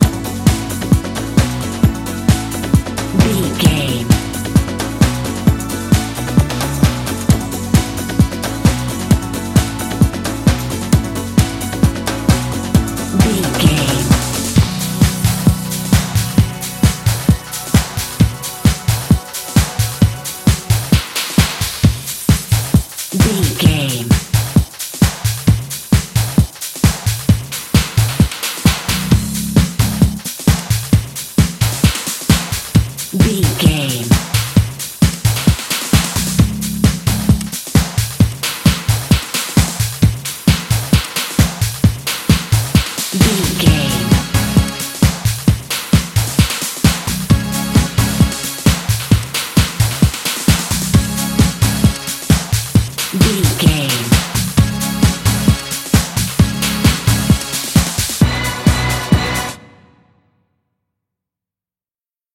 Fast paced
Ionian/Major
Fast
synthesiser
drum machine